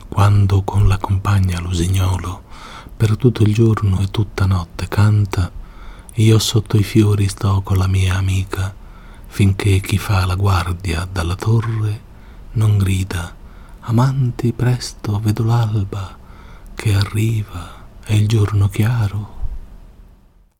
Voce
36 quando con la compagna l usignolo.mp3